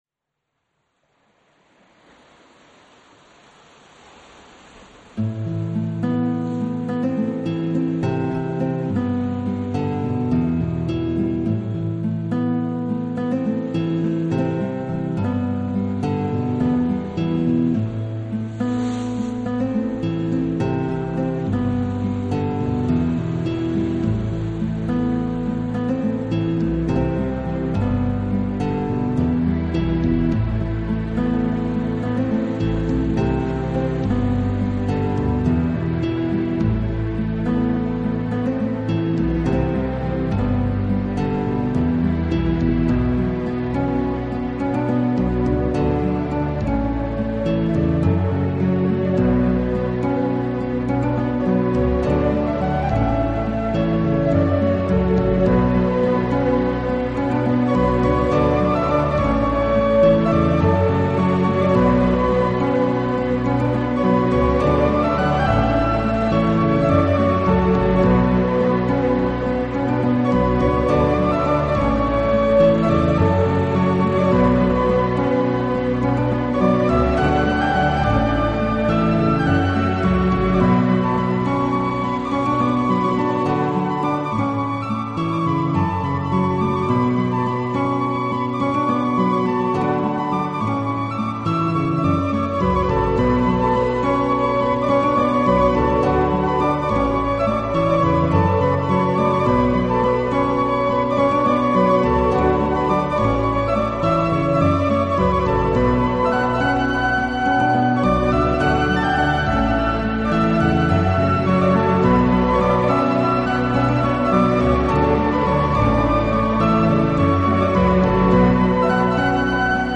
撰寫不同體裁的音樂，結合在哥特式、新古典、金屬。